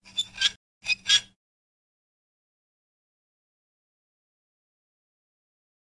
描述：机制激活
标签： 机械 机制 激活
声道立体声